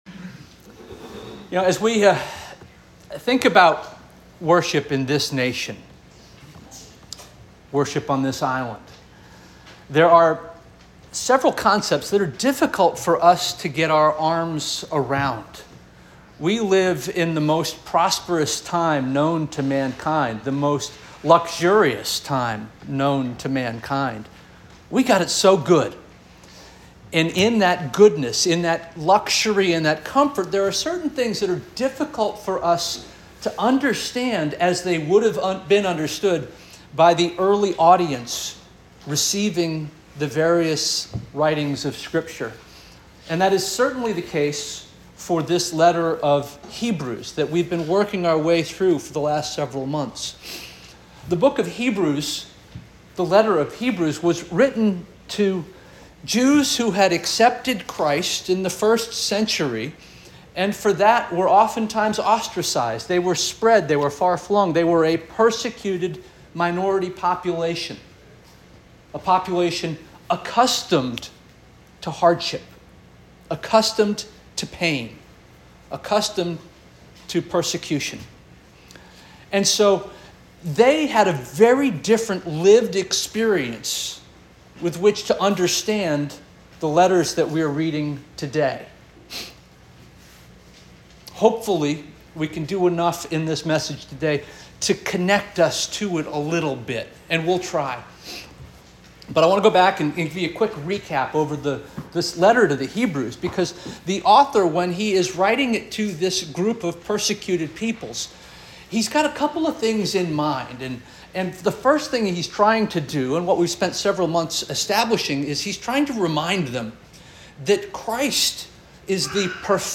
June 2 2024 Sermon - First Union African Baptist Church